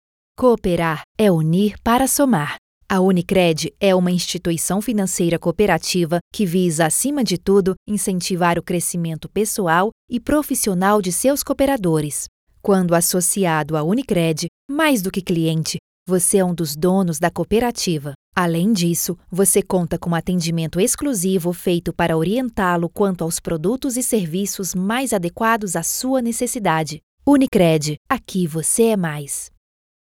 Her voice is described as versatile, friendly, conversational and her voice range goes from 12 to 35 years old.
Sprechprobe: Industrie (Muttersprache):
INSTITUCIONAL CORPORATIVO.mp3